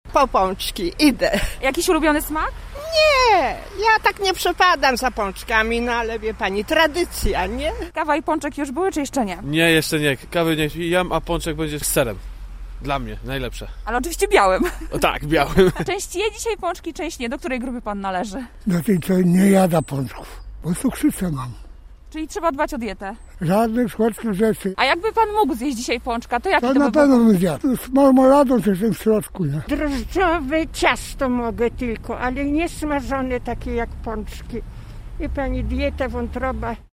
Sprawdziliśmy czy zielonogórzanie również należą do amatorów tej kalorycznej przyjemności: